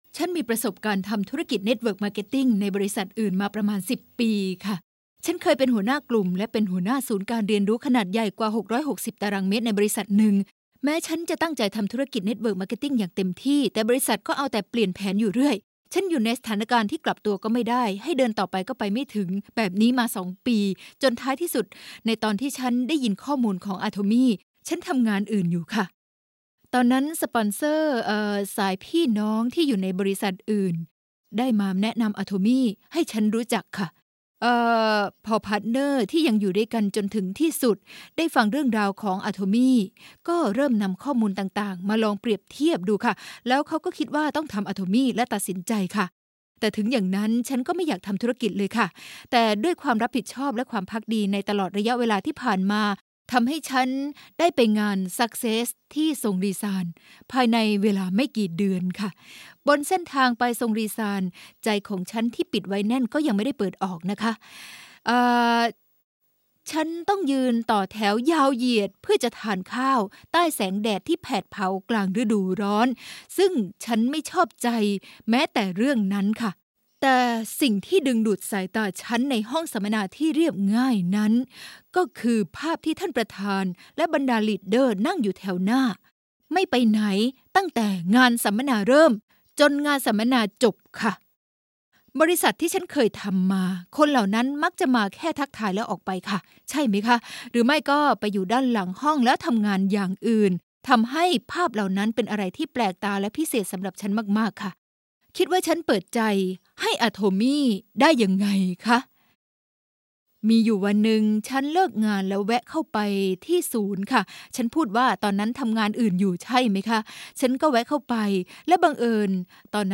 กล่าวต้อนรับ